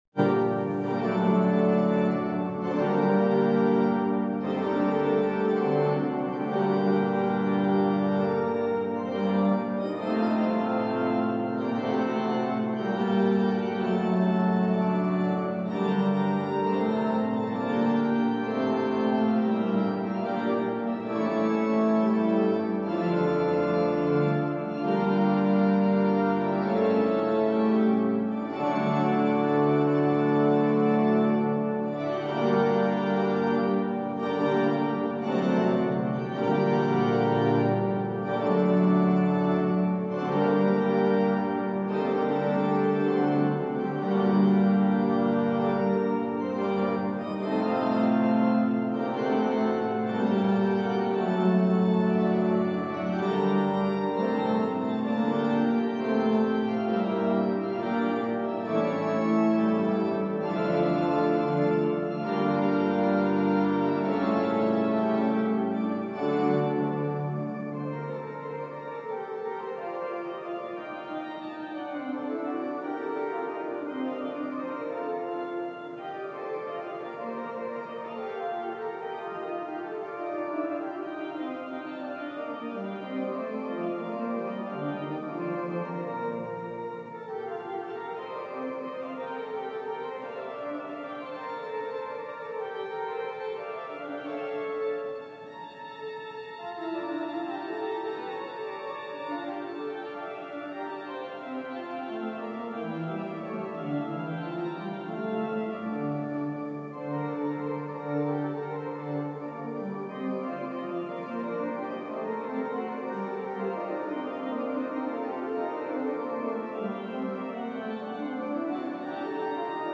orgel um 12